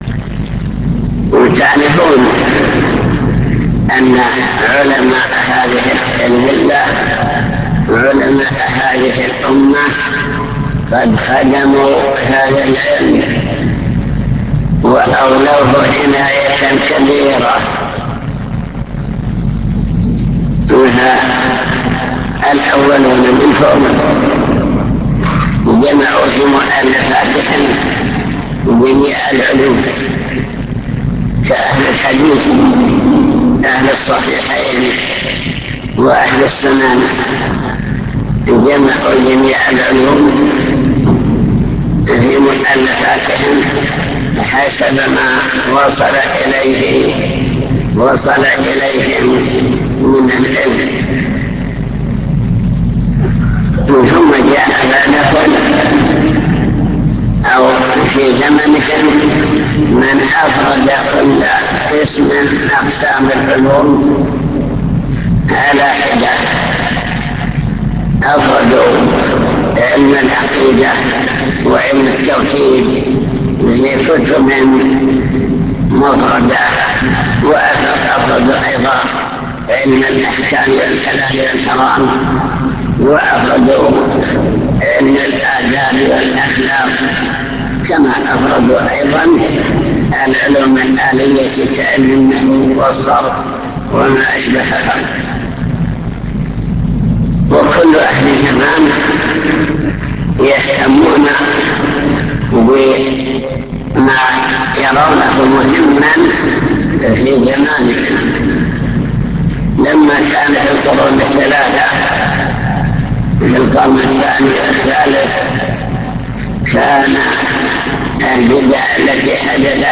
المكتبة الصوتية  تسجيلات - كتب  شرح القواعد الأربعة مقدمة